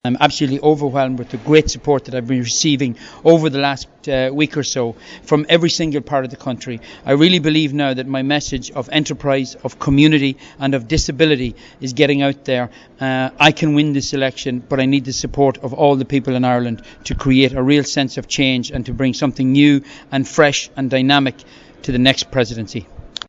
Seán Gallagher at his campaign launch